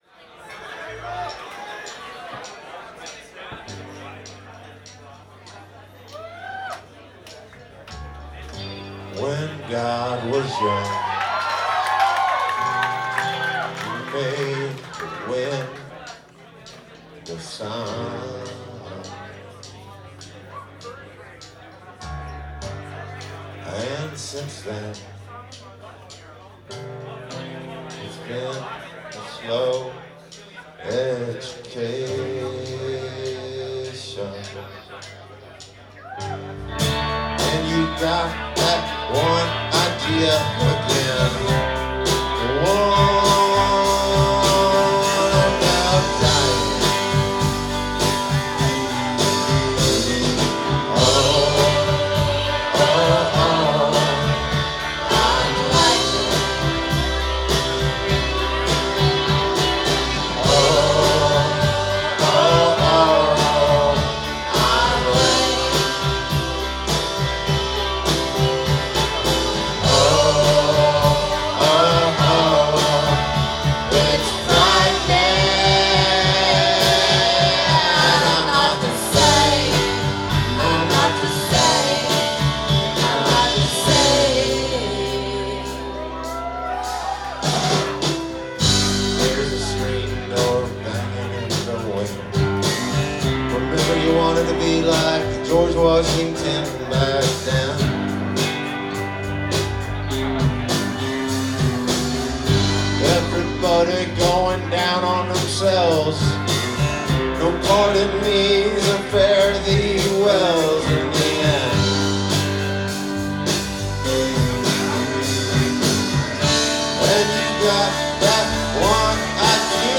Live at The Middle East Downstairs